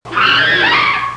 Le chimpanzé | Université populaire de la biosphère
crie, hurle
chimpanze.mp3